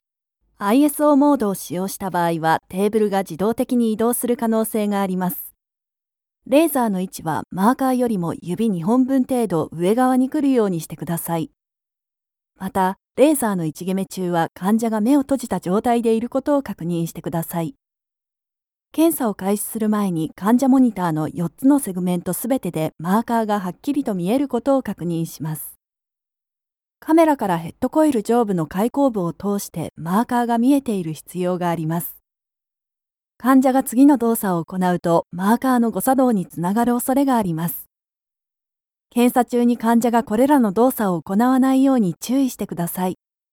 For those of you who want REAL Female JAPANESE voice over! Friendly, sweet, softspoken, believable, caring, warm and comfortable natural voice.
Sprechprobe: eLearning (Muttersprache):
Her voice can be natural, warm, friendly, inviting, yet approachable, suitable, sweet, playful, institutional and much more …!
e-learning-NoBGM.mp3